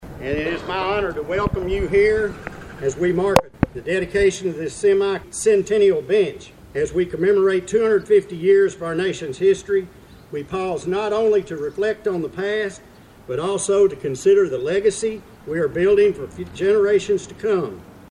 The ceremony was held on the front lawn of the Caldwell County Courthouse with a good attendance of residents.